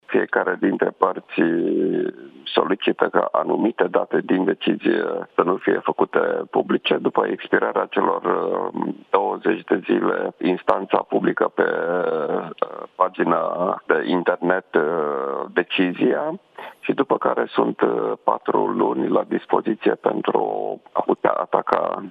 Minstrul Marcel Boloș, într-o declarație la Digi 24, după comunicarea verdictului